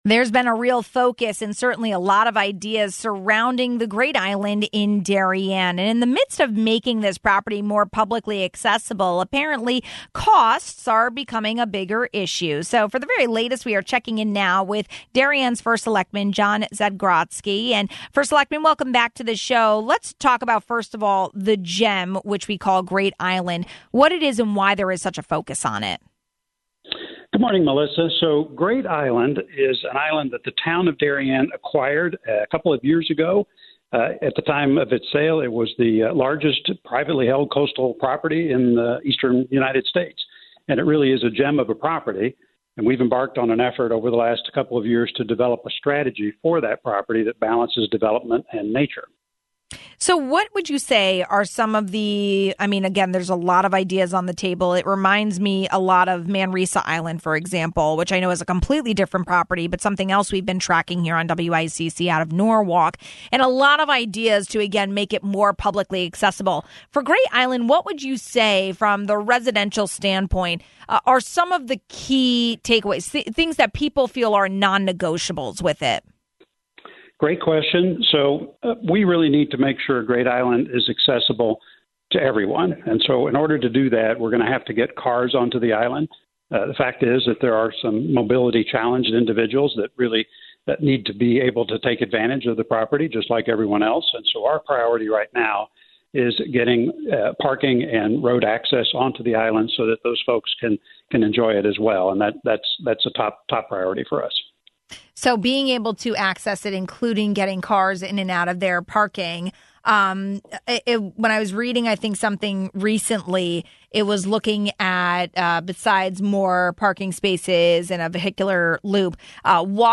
There have been a lot of ideas surrounding Great Island in Darien. Amid making the property more publicly accessible, apparently costs are becoming a bigger issue. For the latest, we checked in with Darien First Selectman Jon Zagrodzky.